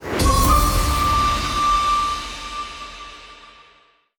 supermegawin_shot.wav